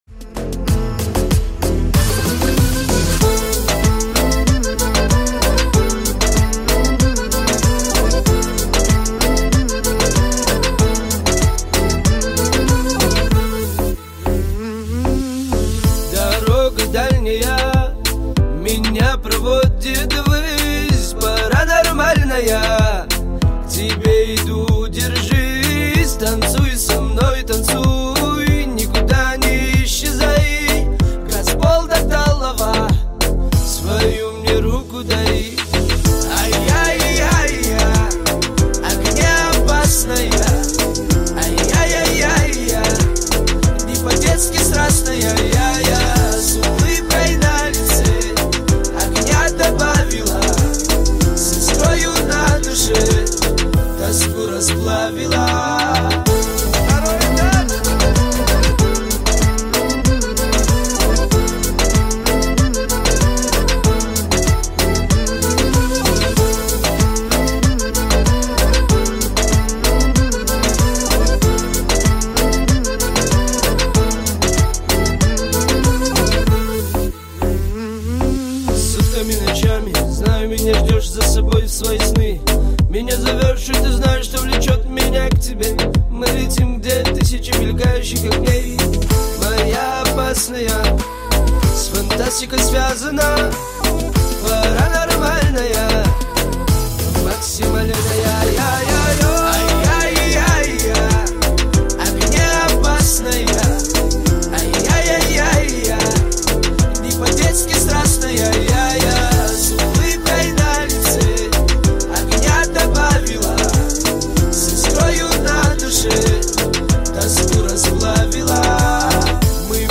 Кавказские песни